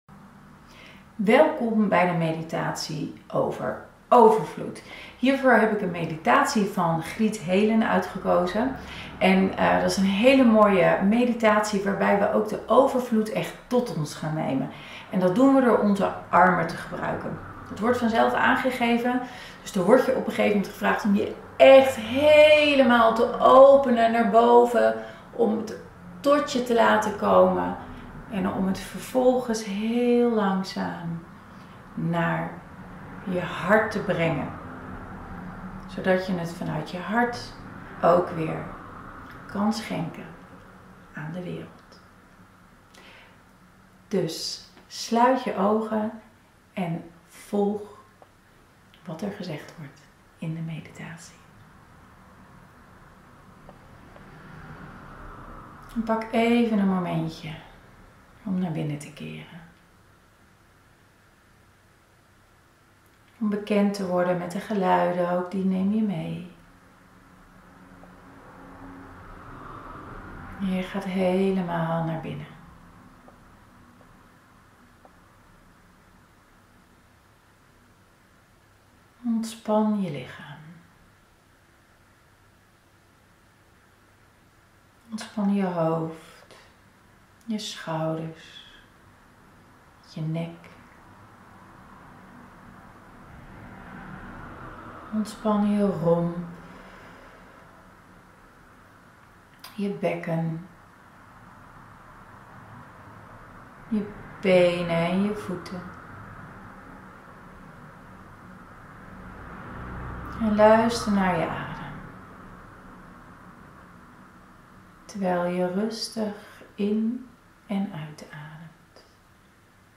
6.1 Meditatie: Overvloed